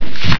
sack_swing.wav